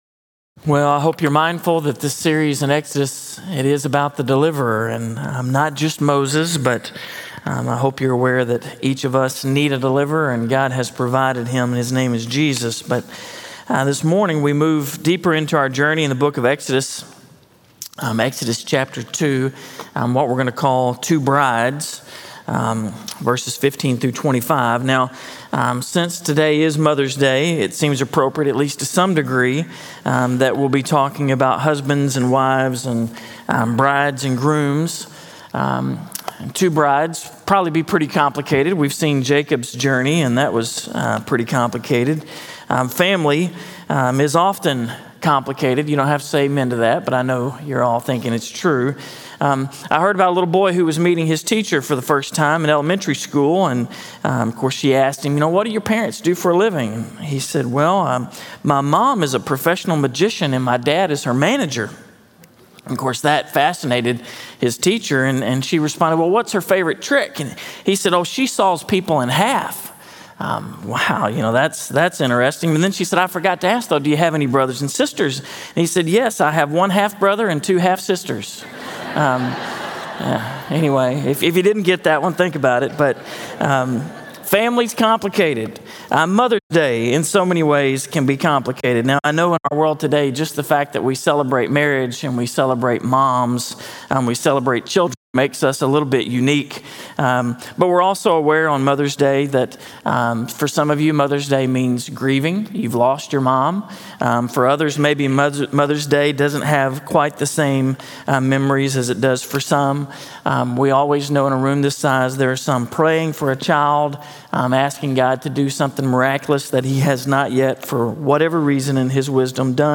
In this message, "Two Brides," from Exodus 2:16-25, we celebrate Mother’s Day and move into week five of our sermon series, “The Deliverer." We will be vividly reminded of God's covenant love.